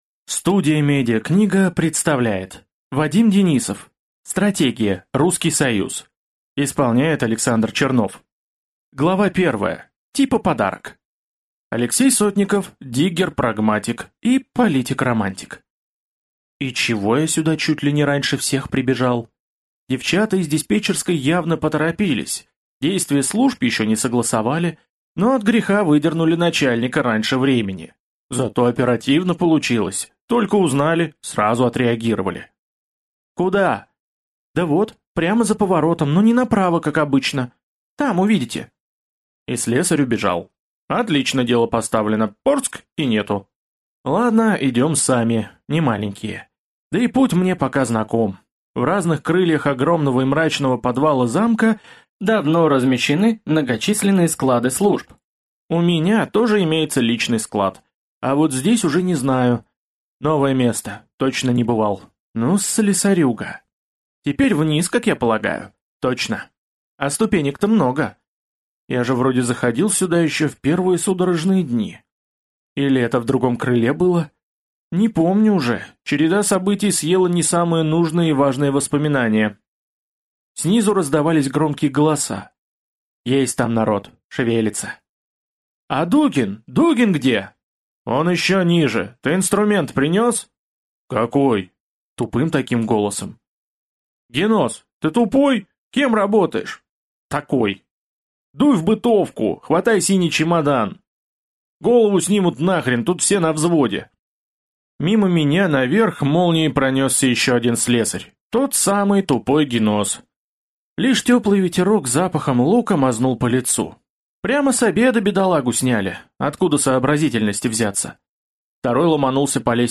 Аудиокнига Стратегия. Русский Союз | Библиотека аудиокниг